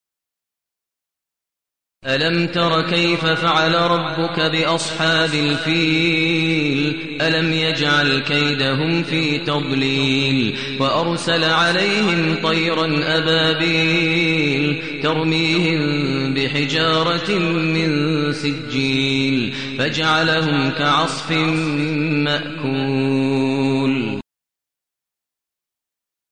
المكان: المسجد النبوي الشيخ: فضيلة الشيخ ماهر المعيقلي فضيلة الشيخ ماهر المعيقلي الفيل